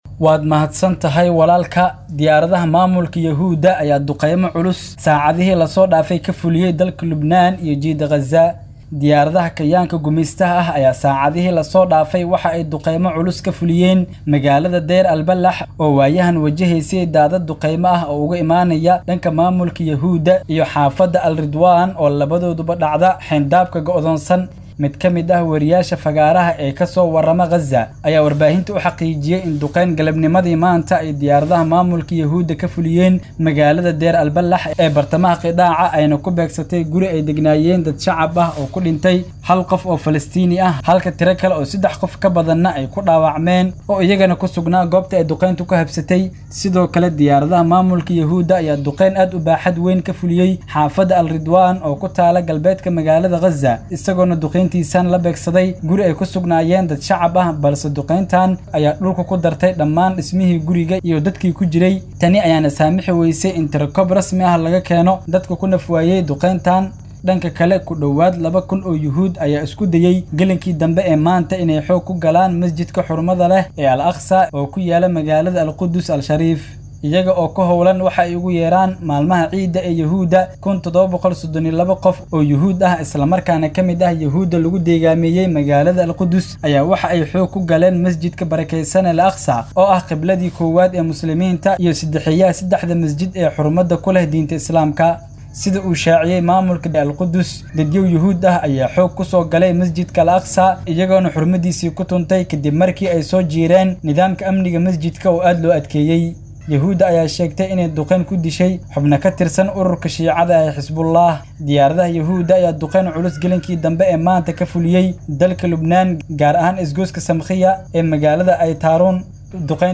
Xubinta Wararka Caalamka oo ku baxda Barnaamijka Dhuuxa Wareysiyada ee idaacadda Islaamiga ah ee Al-Furqaan, waxaa lagu soo gudbiyaa wararkii ugu dambeeyay ee daafaha Caalamka.